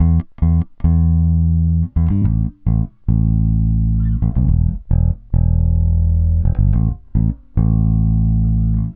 Retro Funkish Bass 01b.wav